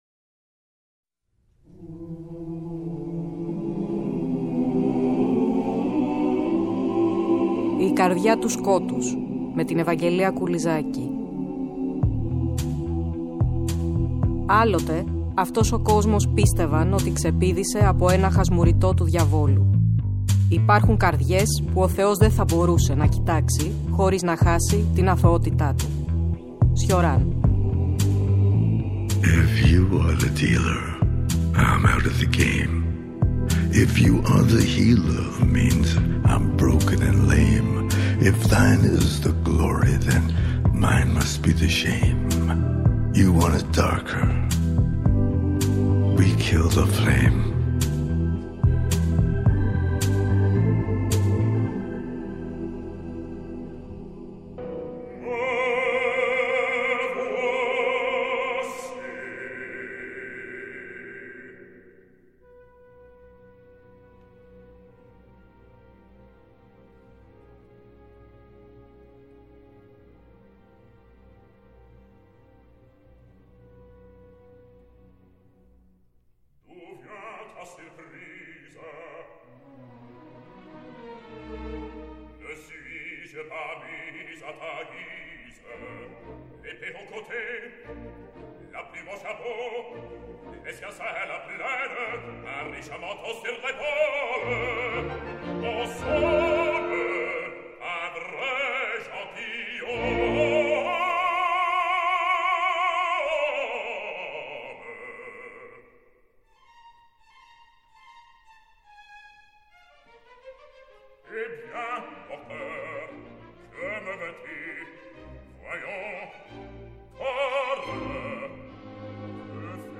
Πλαισιώνουμε μουσικά (και) με αποσπάσματα από το «υβριδικό» έργο του Hector Berlioz (“ légende dramatique en quatre parties “, το χαρακτήριζε ο ίδιος) “La Damnation de Faust” .